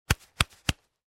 Звуки пальто
Звук легких похлопываний по плечу